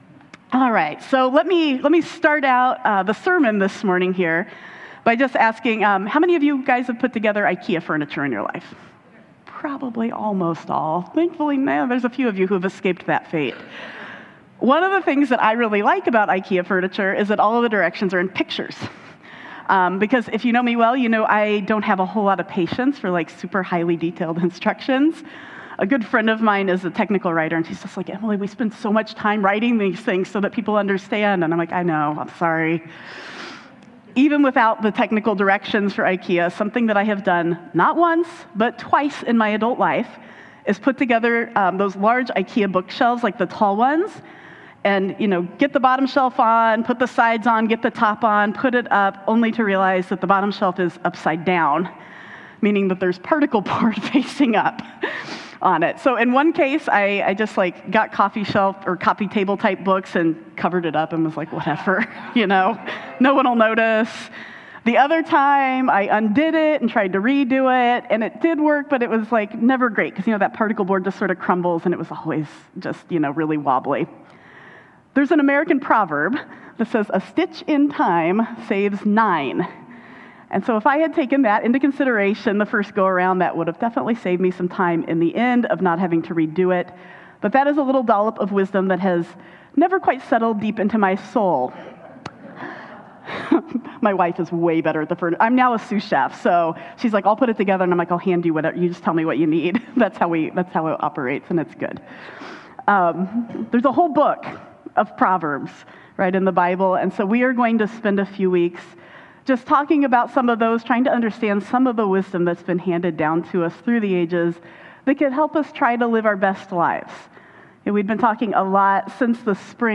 The catch all word for the stuff we learn that makes our lives work well is wisdom. We start a new sermon series this week on the earthy wisdom of the Proverbs and the timeless advice passed down from those who came before us.